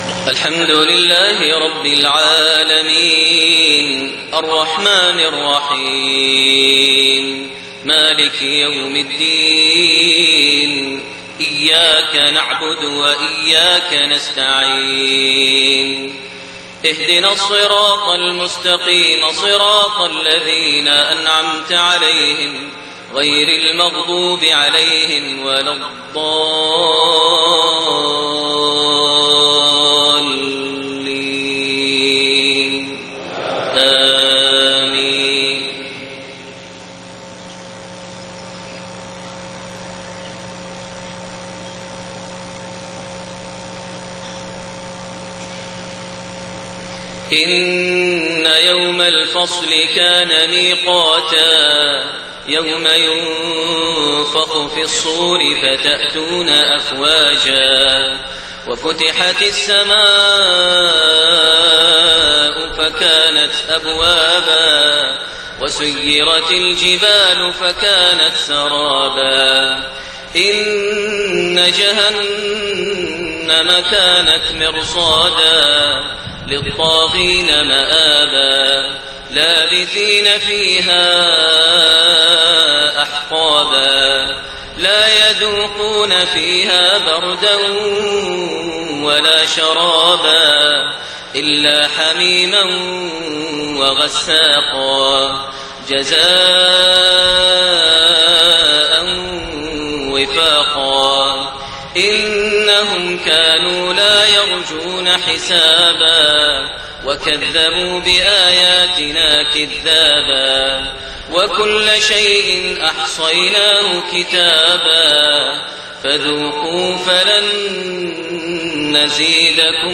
صلاة المغرب2-4-1430 من سورة النبأ 17-40 > 1430 هـ > الفروض - تلاوات ماهر المعيقلي